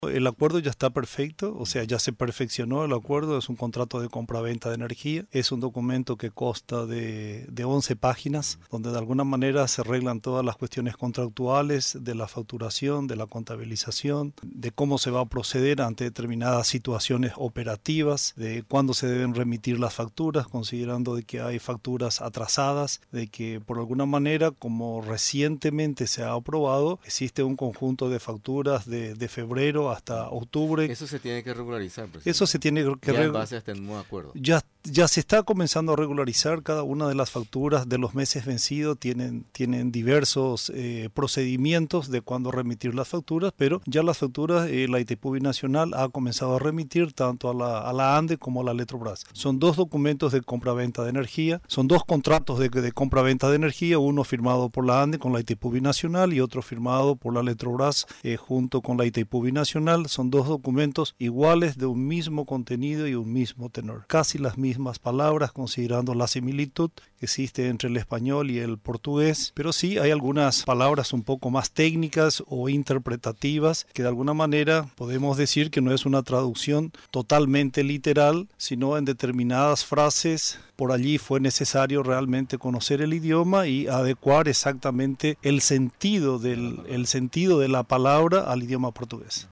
El presidente de la Administración Nacional de Electricidad (ANDE), Luis Villordo, confirmó este lunes, durante su visita a los estudios de Radio Nacional del Paraguay (RNP), que el acuerdo ANDE-Electrobrás, ya fue perfeccionado.